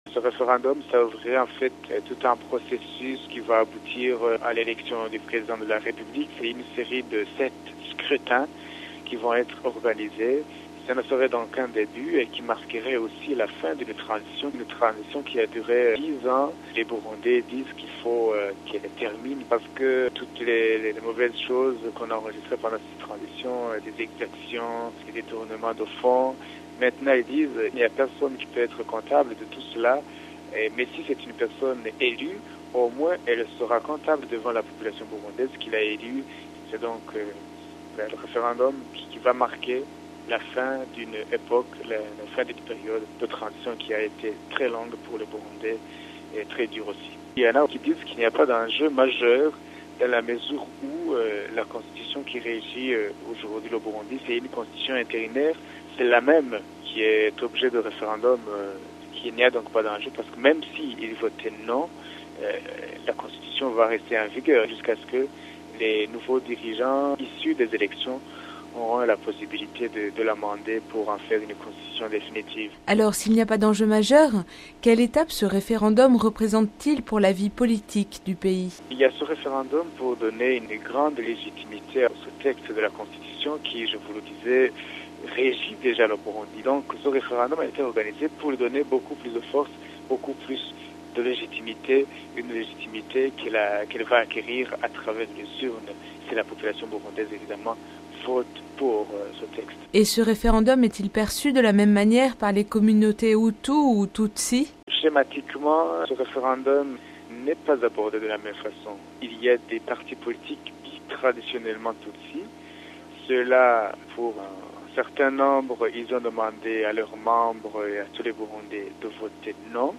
Le point avec